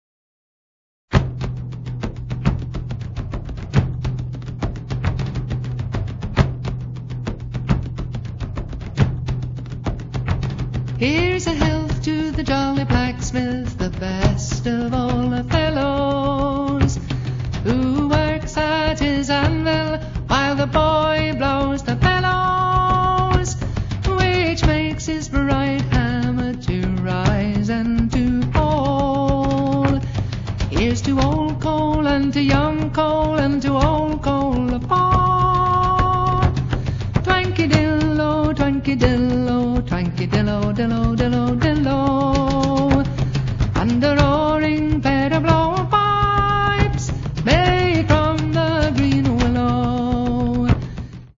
First part, 0:51 sec, mono, 22 Khz, file size: 201 Kb.